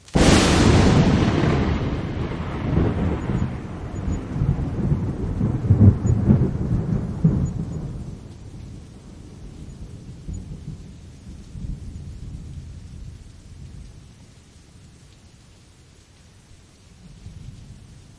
自然的声音 " 风雨雷鸣 01 49秒
描述：记录在家，佛罗里达州暴雨。自然风天气风暴雷雨雷雨雷电雷雨
Tag: 雷暴 暴雨 气候 雷电 暴雨 雷暴 自然